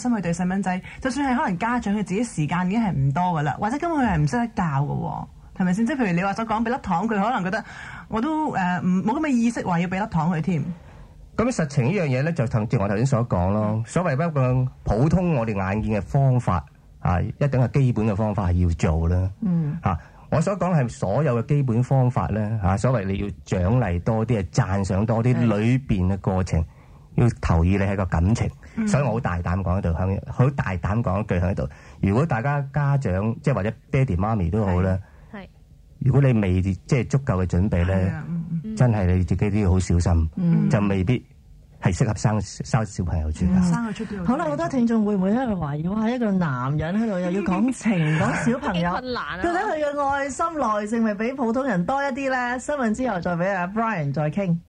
就是可能說出很多成年已婚夫婦的想法，今天讓大家繼續分享多一些他的訪問。